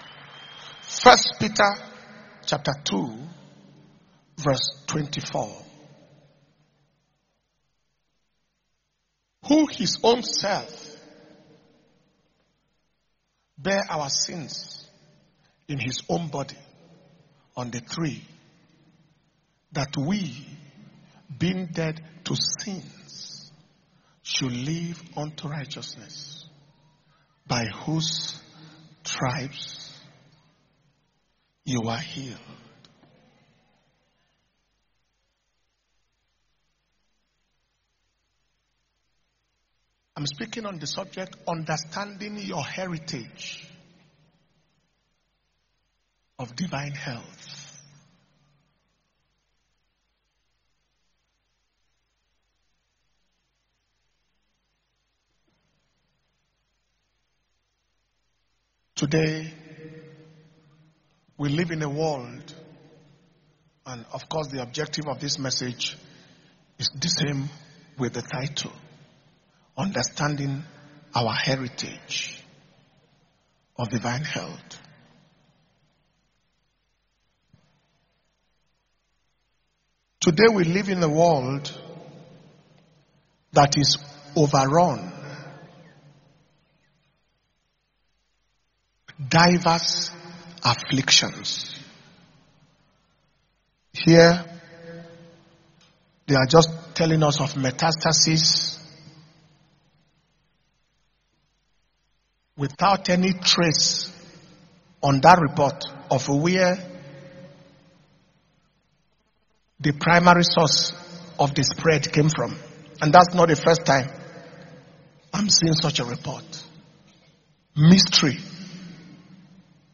Kingdom Power And Glory World Conference November 2020 Day 2 morning session